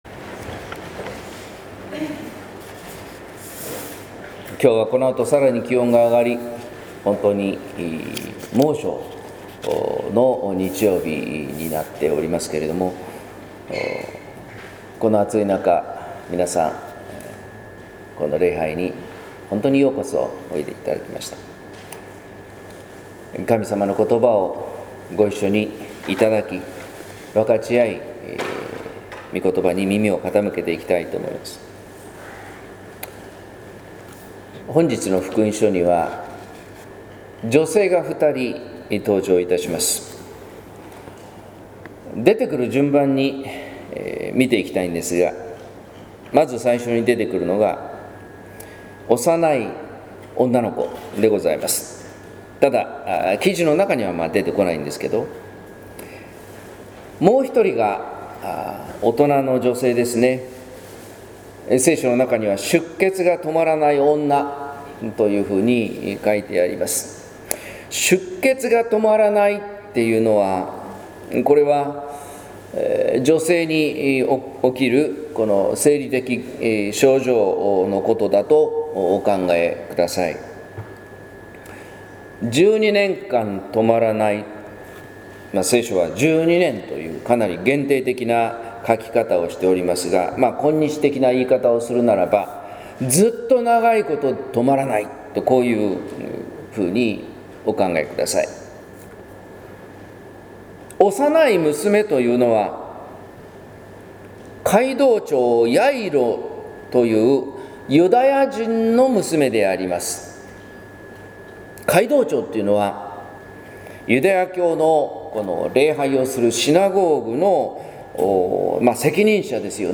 説教「二人の女とイエスの癒し」（音声版） | 日本福音ルーテル市ヶ谷教会